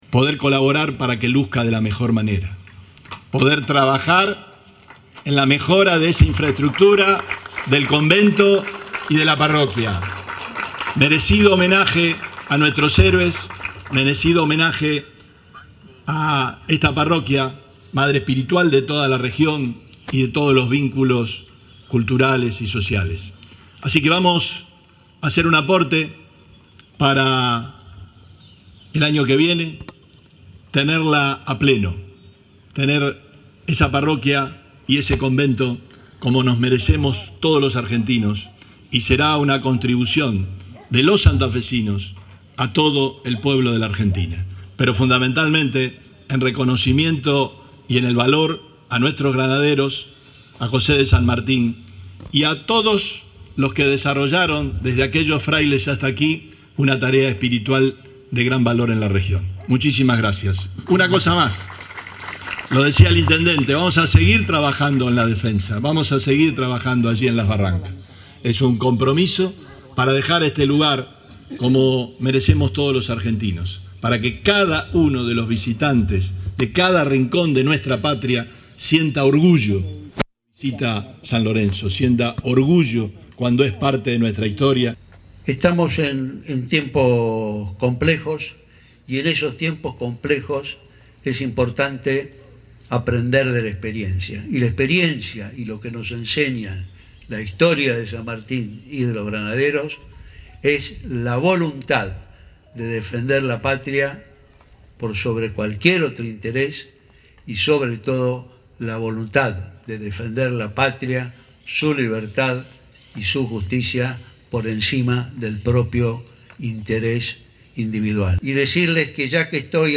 Perotti en el acto en conmemoración por el 209° Aniversario del Combate de San Lorenzo